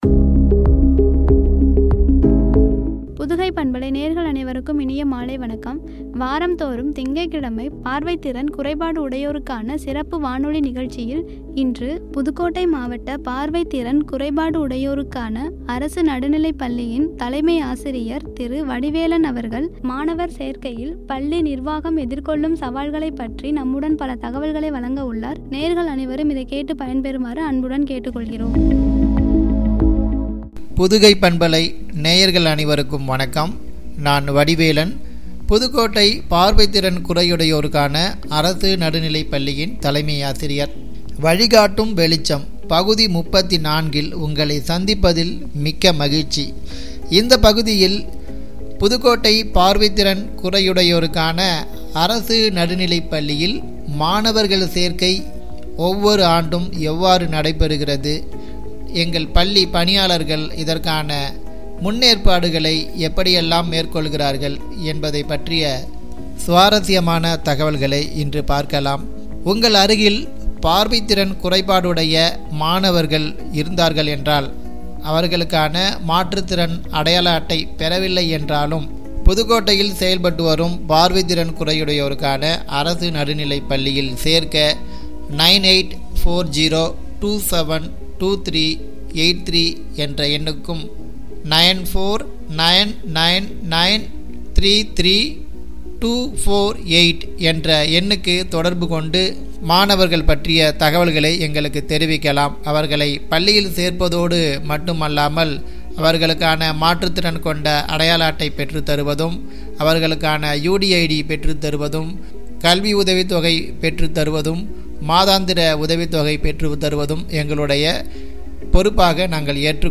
பார்வை திறன் குறையுடையோருக்கான சிறப்பு வானொலி நிகழ்ச்சி
குறித்து வழங்கிய உரை.